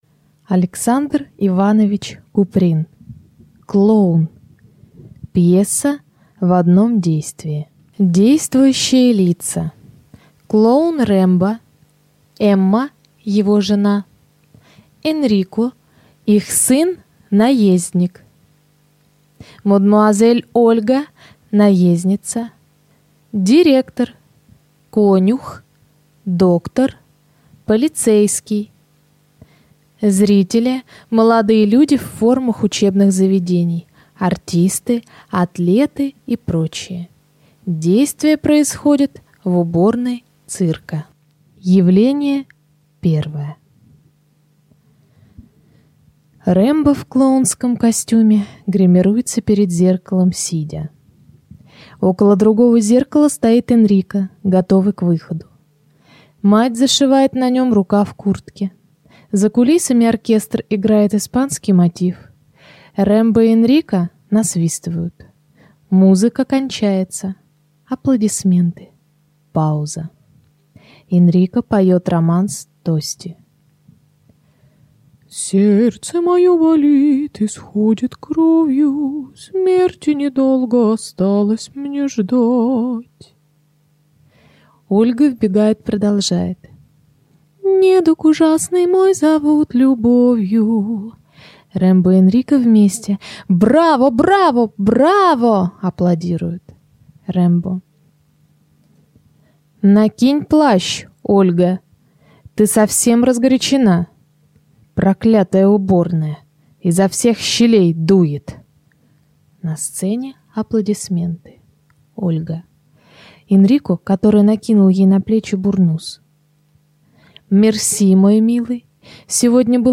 Аудиокнига Клоун | Библиотека аудиокниг